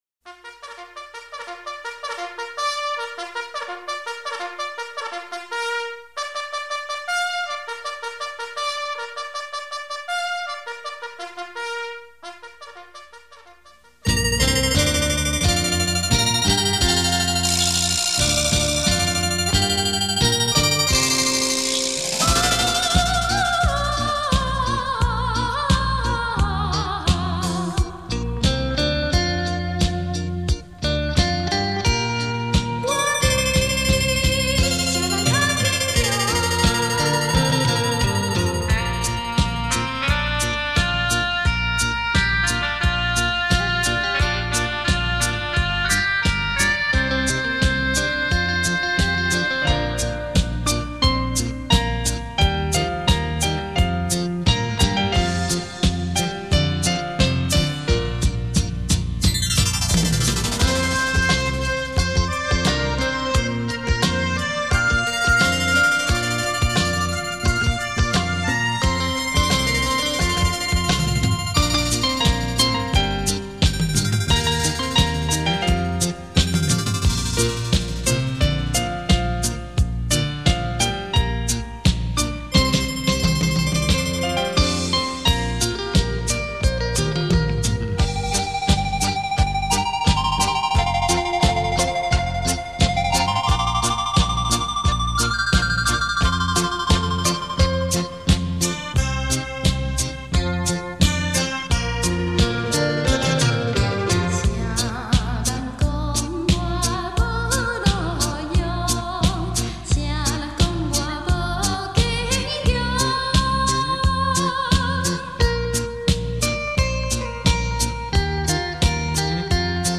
超時空特殊效果音樂 立體音效 百萬名琴魅力大出擊
電聲發燒珍品·值得您精心收藏·細細聆賞...